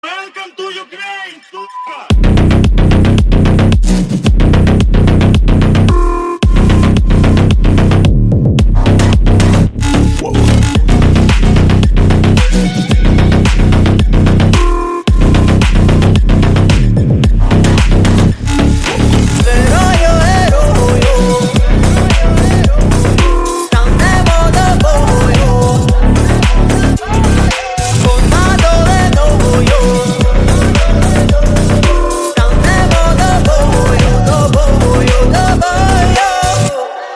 • Качество: 128, Stereo
жесткие
мощные басы